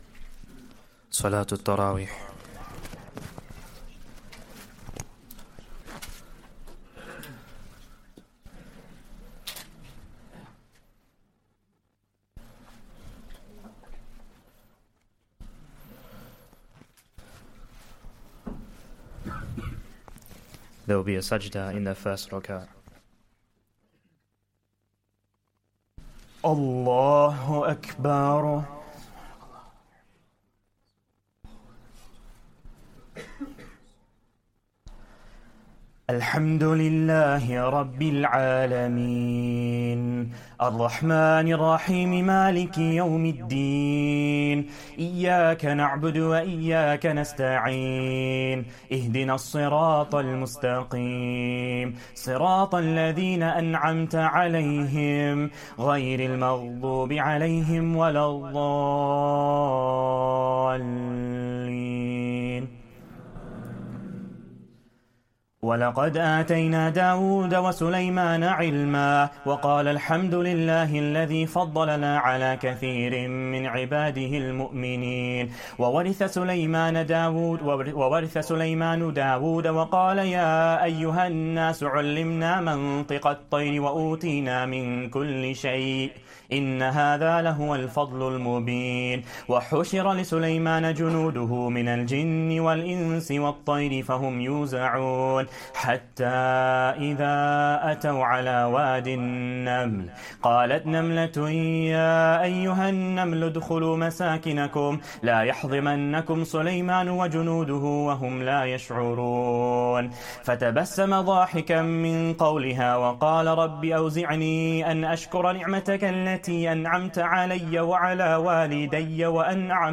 Taraweeh Prayer 16th Ramadan